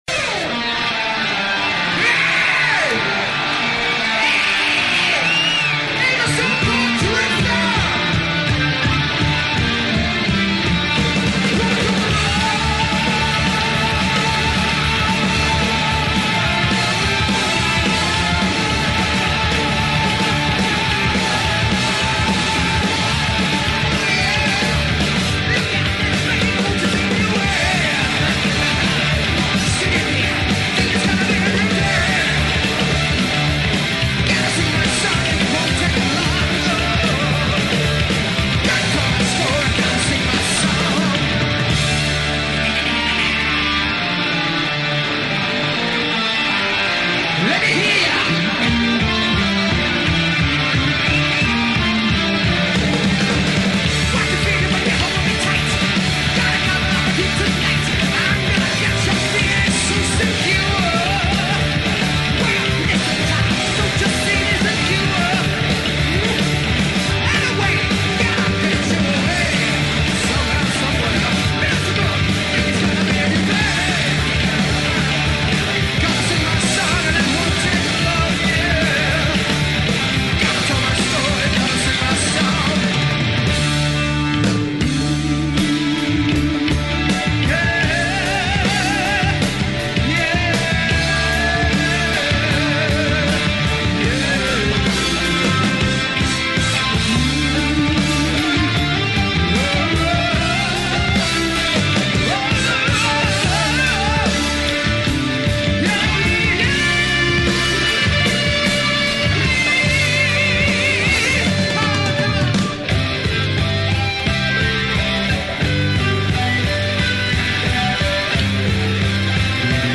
recorded live @ the Palladium, NYC, NY on June 29th, 1982